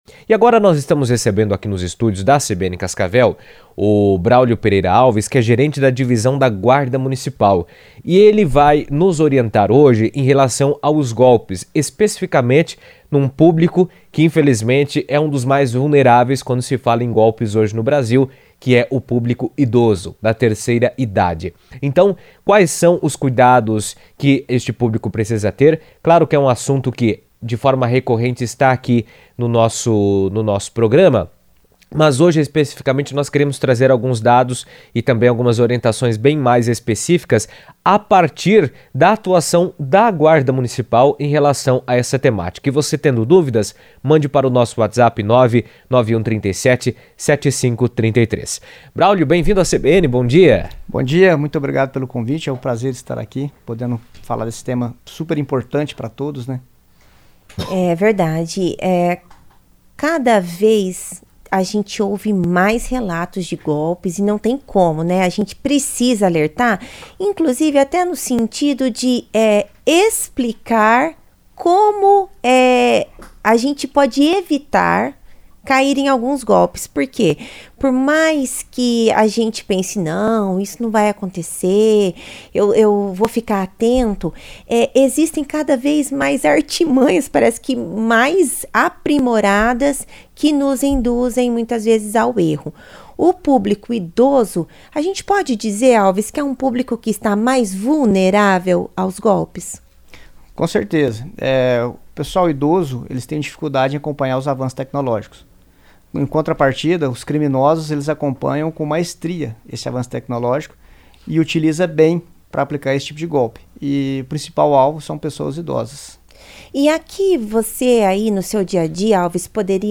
comentou na CBN as principais formas de prevenção e orientou sobre os cuidados que devem ser adotados para evitar cair em golpes.